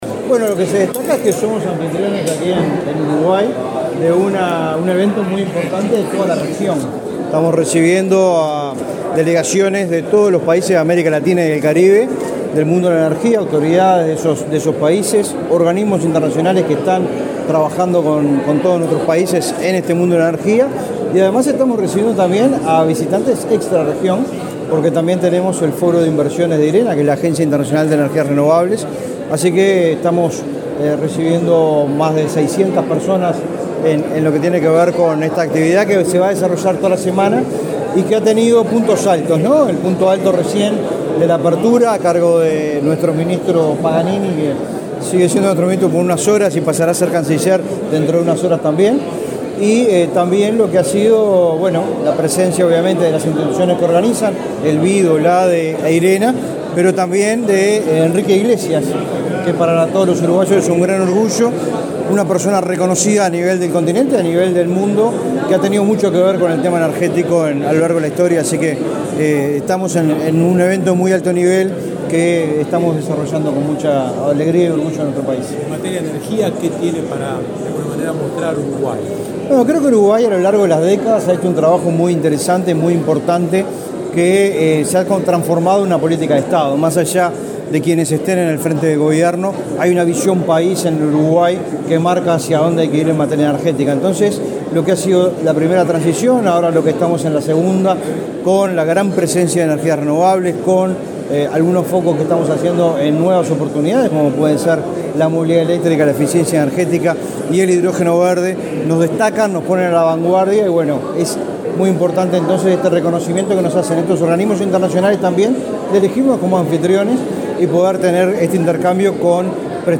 Declaraciones del director nacional de Energía, Fitzgerald Cantero
Luego, dialogó con la prensa.